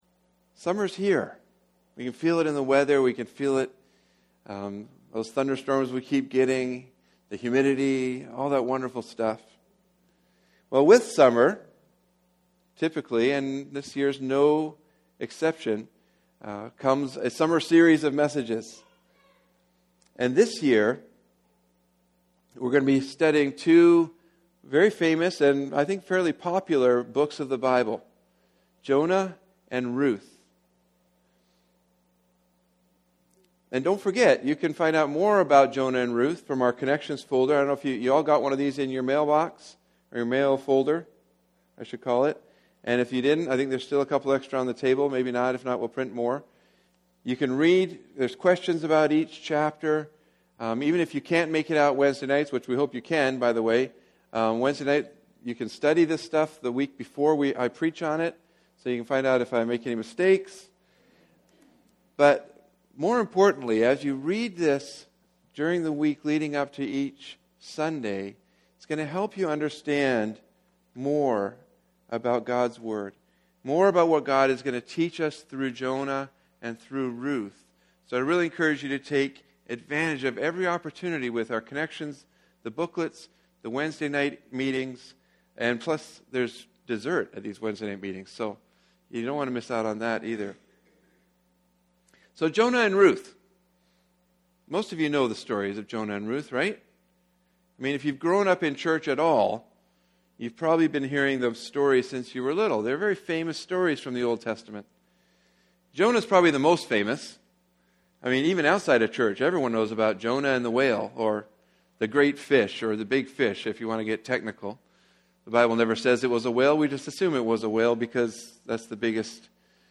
Sermons | Ritson Road Alliance Church
A Testament to God's Purpose Ruth 4 Guest Speaker August 27, 2017 Ruth's Obedience Jonah & Ruth: A Contrast in Character...